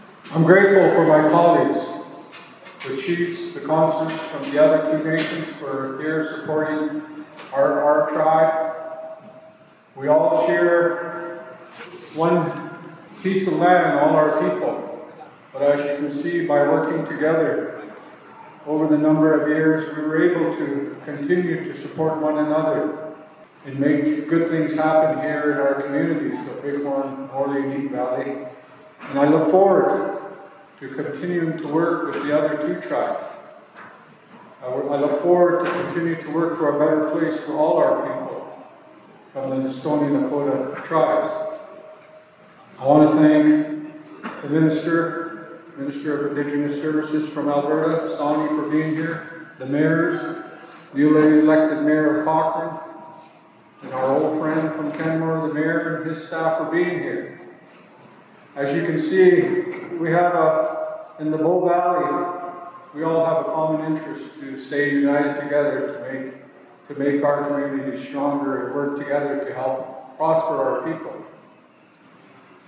Bearspaw First Nation held an Inauguration Ceremony for their elected Chief and Council
During a speech at the Inauguration, Chief Dixon expressed his thanks.